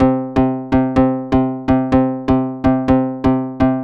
cch_synth_loop_mania_125_Dm.wav